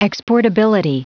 Prononciation du mot exportability en anglais (fichier audio)
exportability.wav